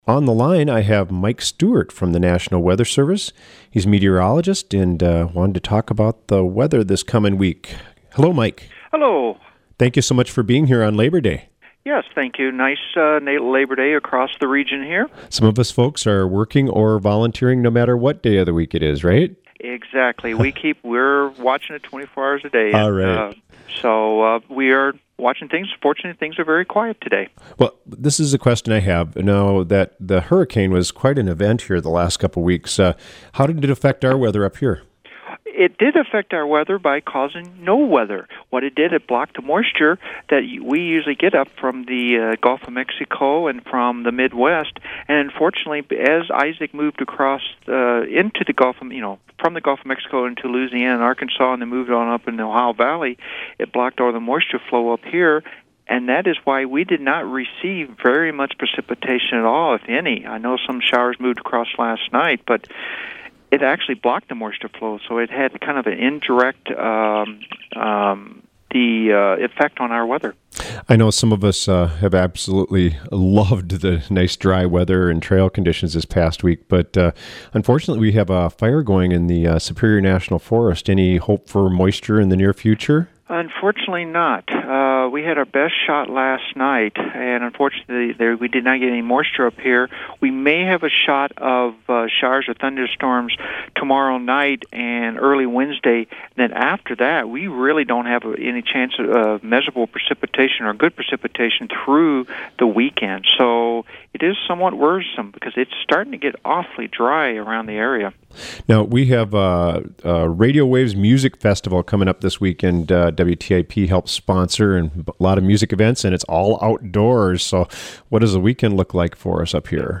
spoke with National Weather Service meteorologist